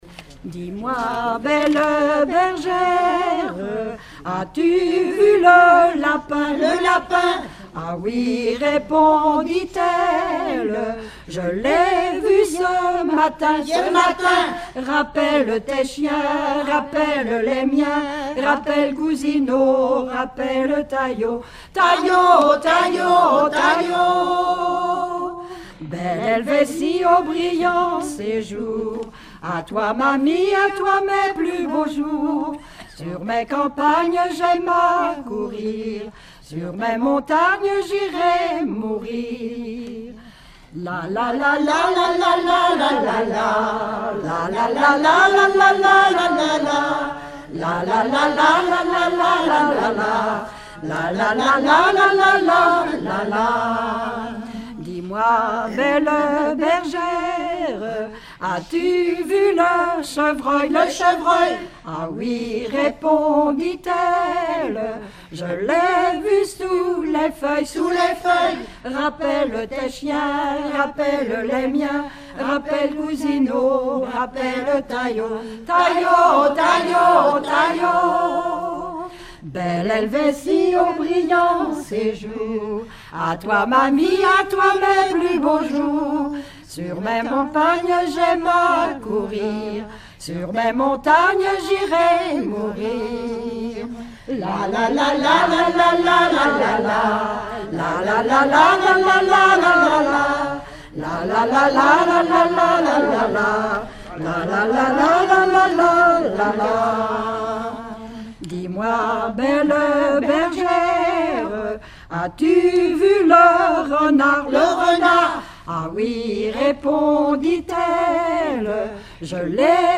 Genre énumérative
Regroupement de chanteurs du canton
Pièce musicale inédite